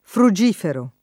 [ fru J& fero ]